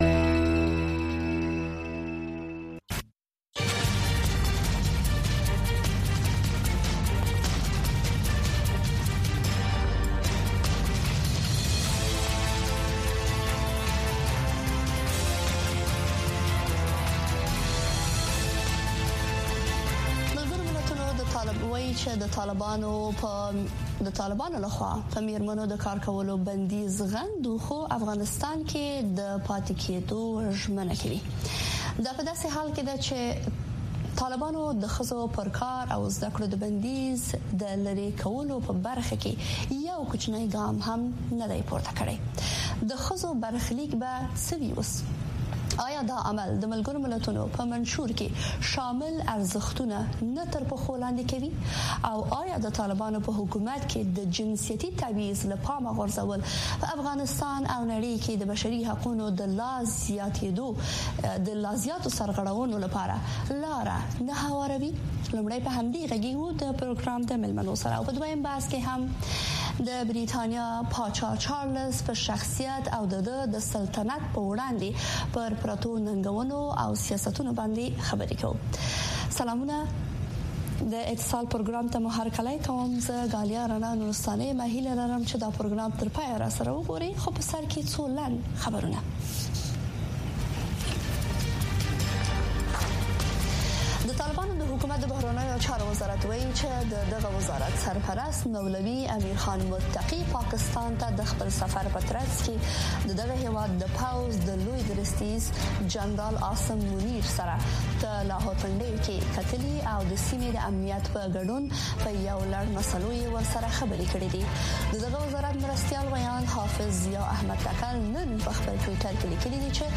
په دې خپرونه کې د چارواکو، شنونکو او خلکو سره، مهمې کورنۍ او نړیوالې سیاسي، اقتصادي او ټولنیزې مسئلې څېړل کېږي. دغه نیم ساعته خپرونه له یکشنبې تر پنجشنبې، هر مازدیګر د کابل پر شپږنیمې بجې، په ژوندۍ بڼه خپرېږي.